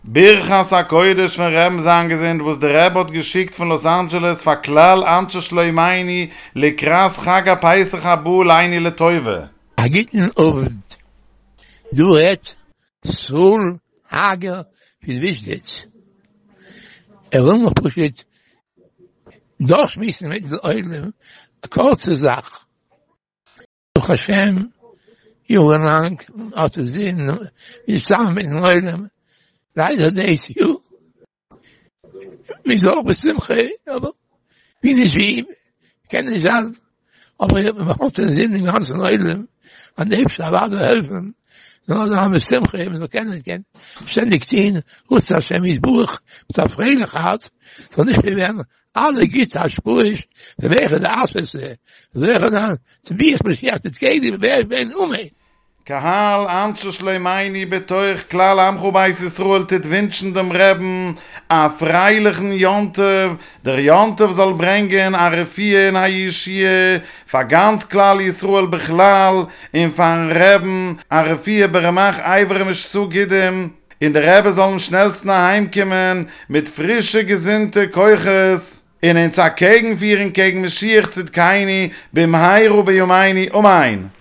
- ברכת הקודש -
לוס אנג'לס